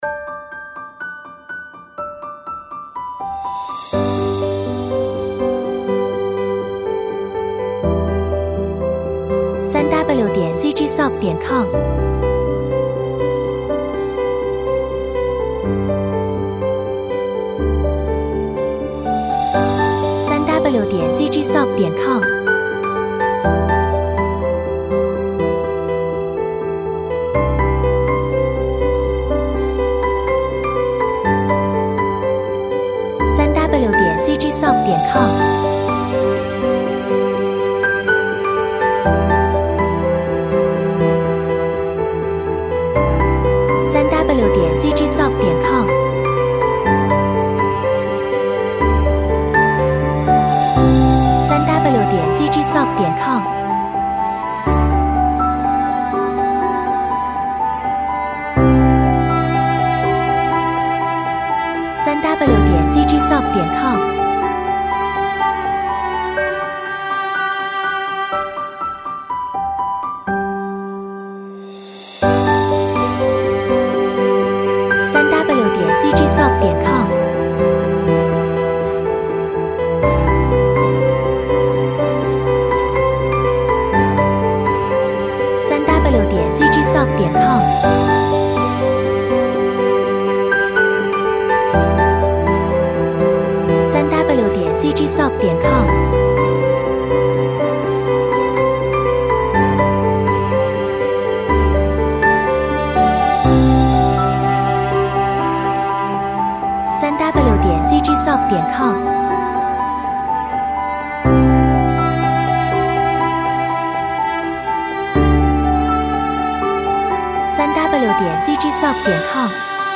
令人惊叹的琴弦创造了一个令人振奋和鼓舞人心的情绪，明亮的三角钢琴增加了光线和积极性。
采样率:16位立体声，44.1 kHz
时间（BPM）:123